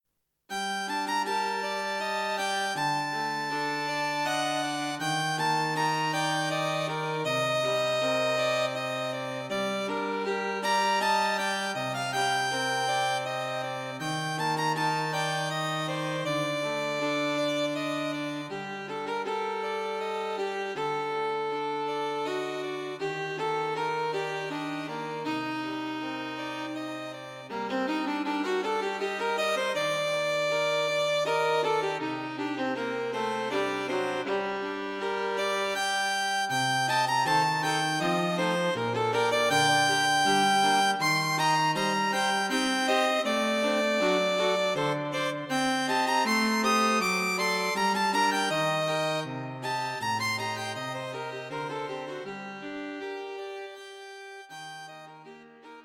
Light-hearted and fun to play.